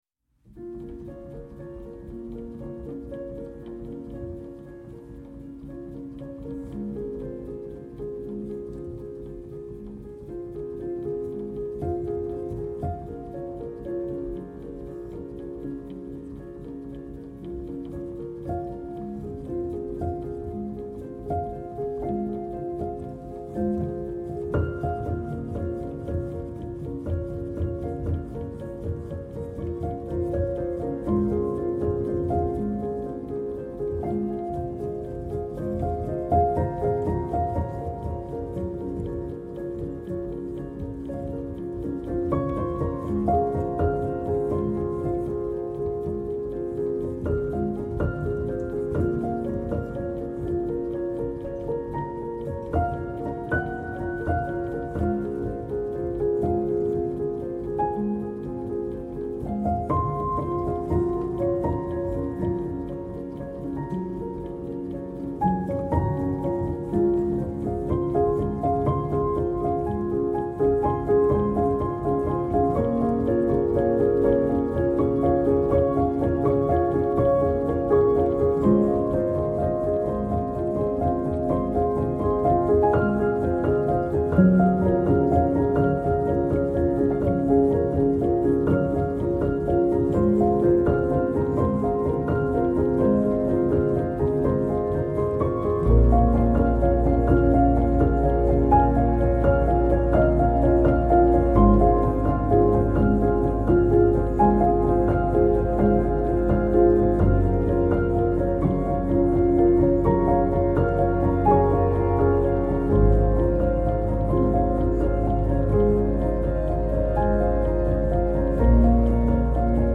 آهنگ پیانو